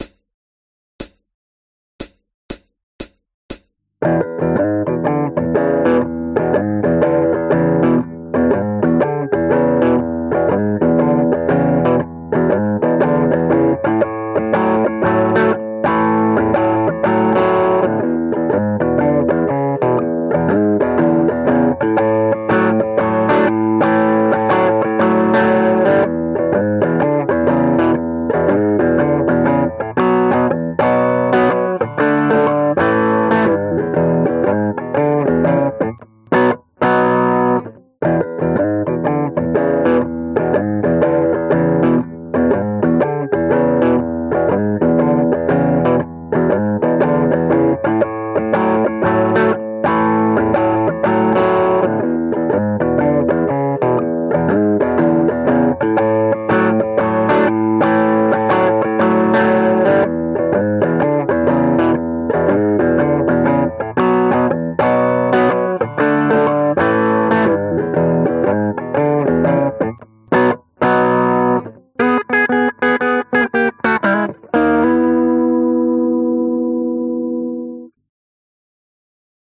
蓝调摇滚120
描述：Blues Rock Shuffle Guitar 120 BPM in E.
Tag: 布鲁斯 吉他 摇滚